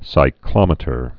(sī-klŏmĭ-tər)